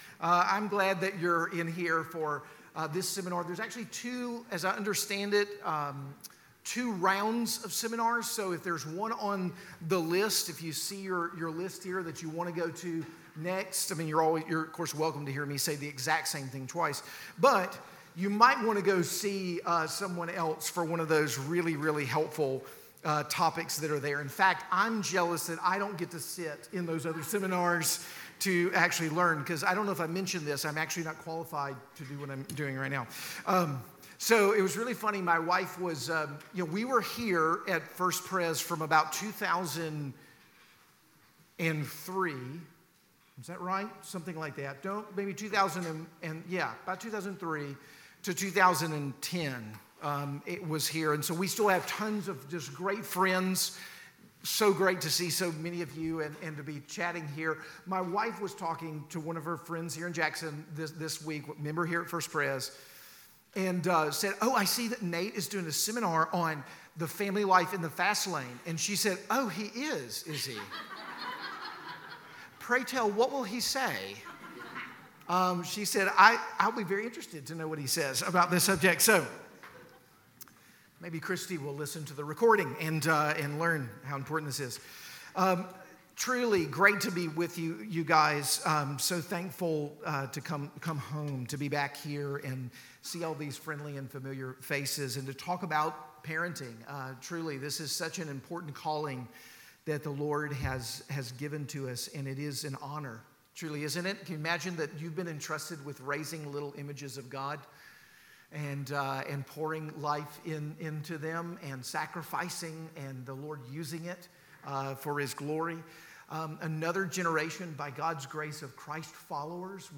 Parenting Seminar 2023